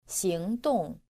• xíngdòng